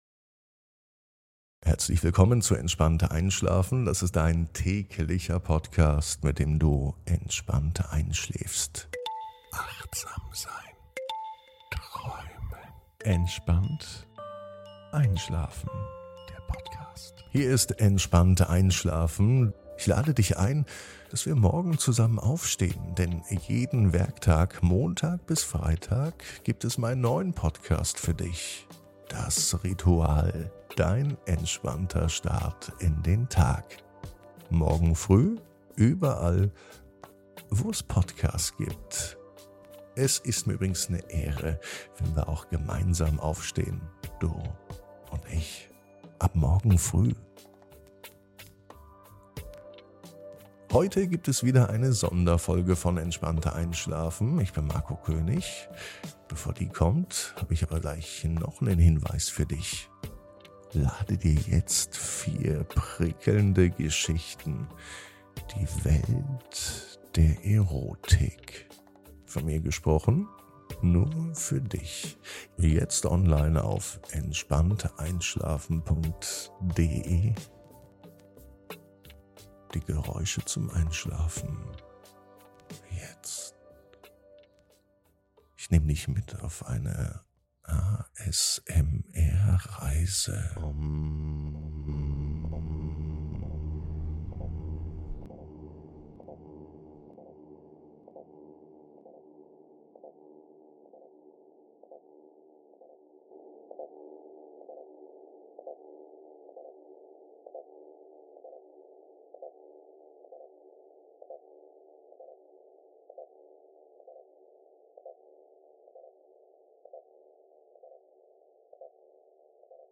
Klangzauber der Klangschale: Entspannende Geräusche zum Einschlafen ~ Entspannt einschlafen - Meditation & Achtsamkeit für die Nacht Podcast
In dieser Folge entführen wir dich in die zauberhafte Welt der Klangschalen. Tauche ein in die tiefen und beruhigenden Klänge, die von der Klangschale erzeugt werden und eine Atmosphäre der Entspannung schaffen. Spüre die Schwingungen, die sanft durch den Raum vibrieren und deinen Geist zur Ruhe bringen. Die harmonischen Töne der Klangschale wirken stressreduzierend und helfen dir dabei, den Alltag loszulassen.